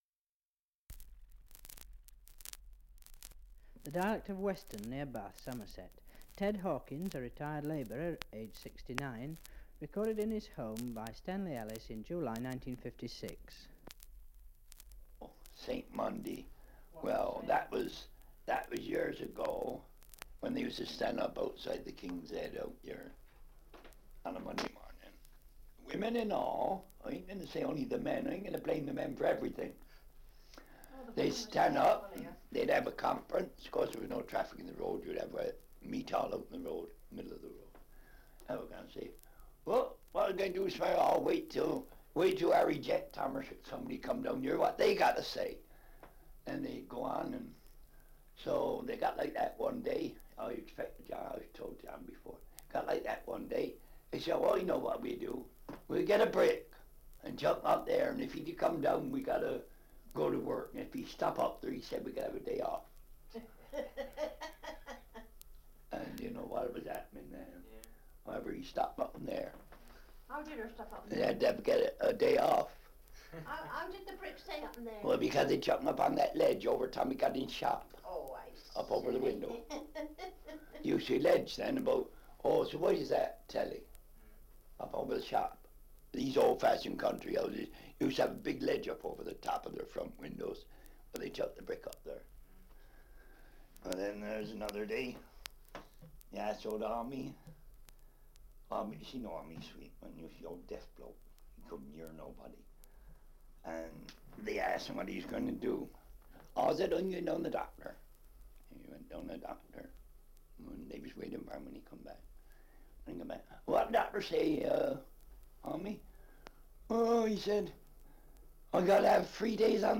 Survey of English Dialects recording in Weston, Somerset
78 r.p.m., cellulose nitrate on aluminium